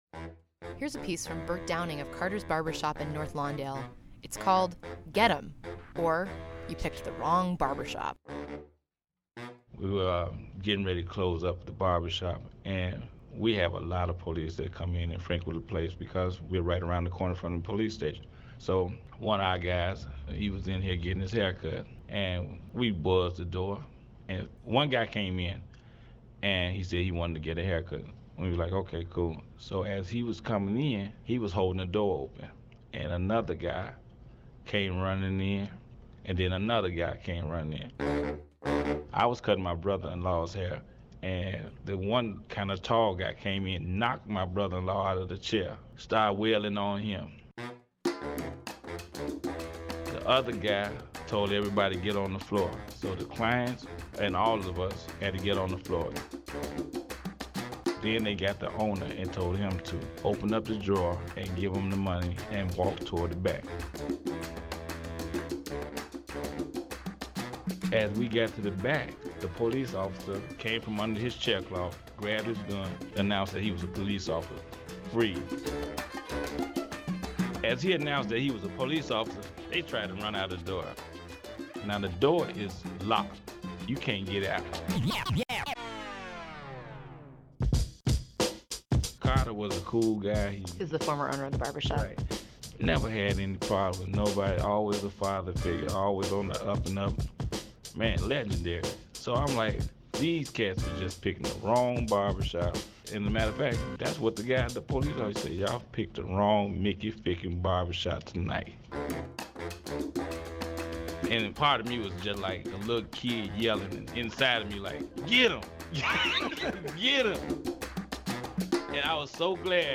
Here are some clips that came from the various live remote broadcasts we’ve staged at Carter’s Barbershop over the past two years.